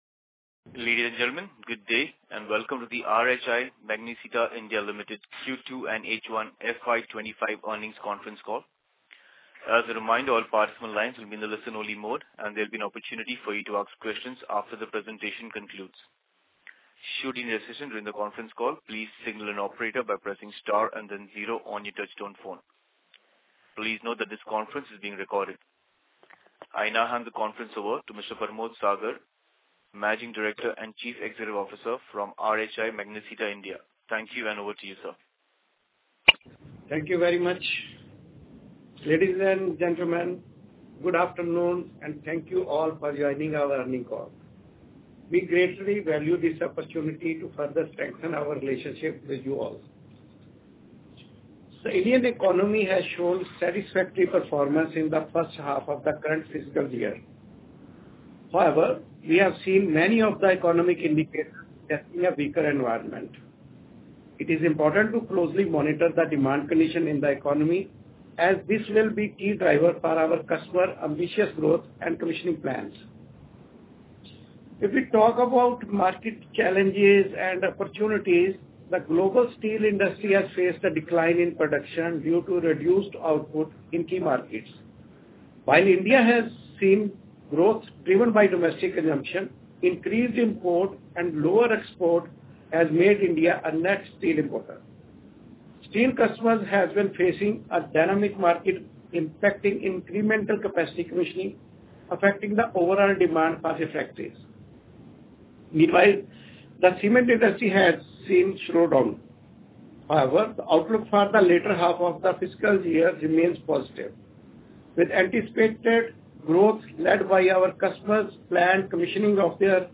Concalls
q2fy25-earnings-call-audio.mp3